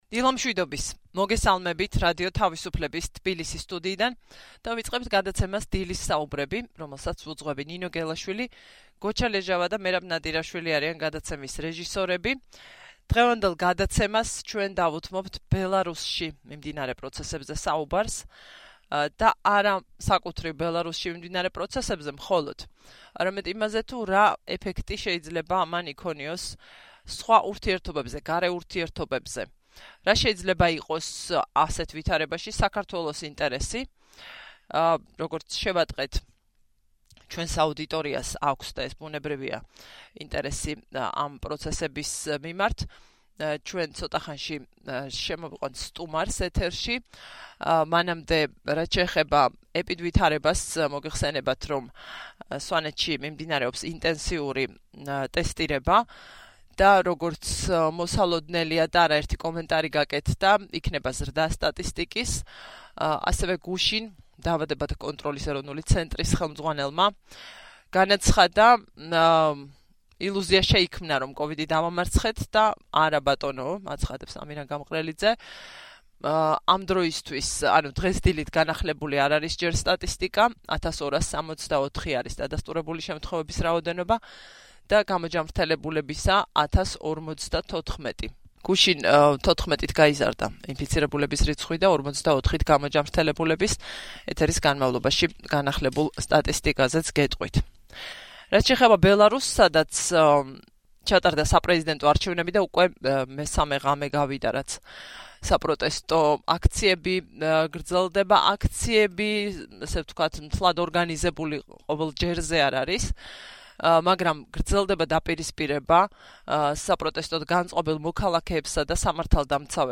რადიო თავისუფლების ეთერში ილაპარაკა ბელარუსის საპრეზიდენტო არჩევნებსა და მის შემდგომ პროცესებზე, რაც ძალიან აშფოთებს დასავლეთს და ამის დასტურია 9 აგვისტოს შემდგომი მკაცრი განცხადებები.